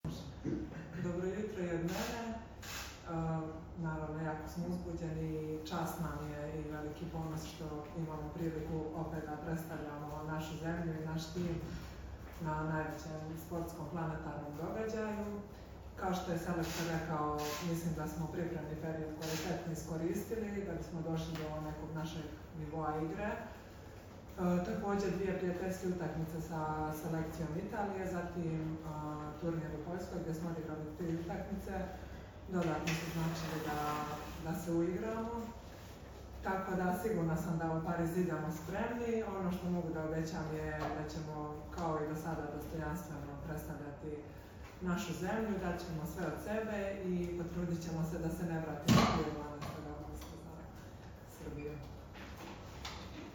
Tim povodom je Olimpijski komitet Srbije na Aerodromu “Nikola Tesla” organizovao konferenciju za novinare.
Izjava Tijane Bošković